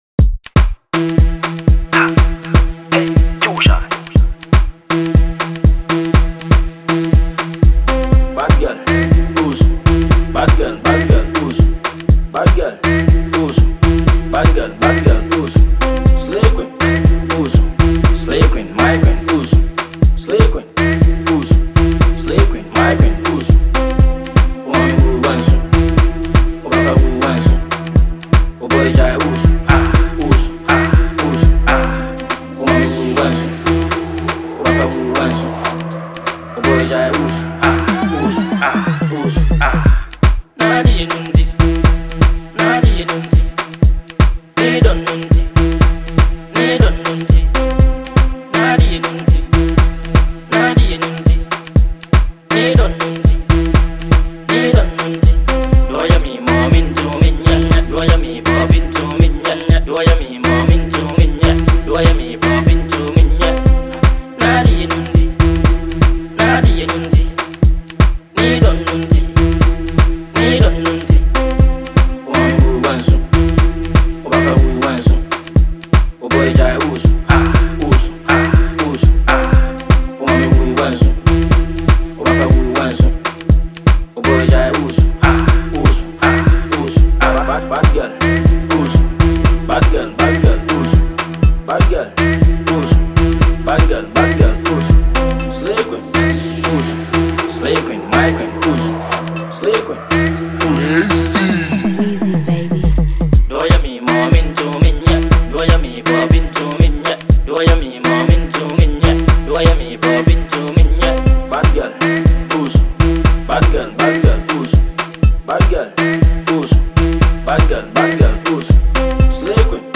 This club banger
it will get you dancing without noticing